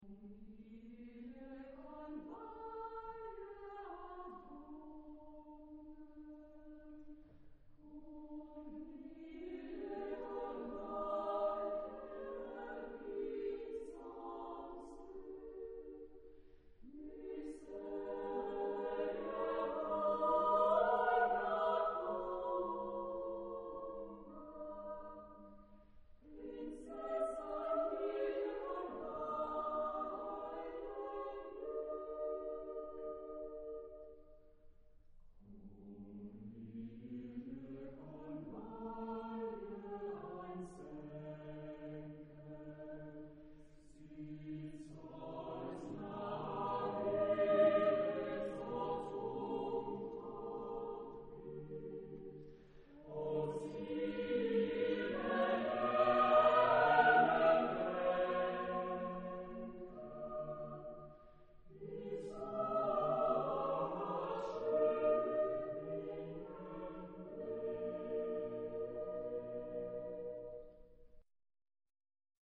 Genre-Style-Form: Secular ; Popular ; Partsong
Type of Choir: SSAATTBB  (8 mixed voices )
Tonality: G minor
Discographic ref. : Internationaler Kammerchor Wettbewerb Marktoberdorf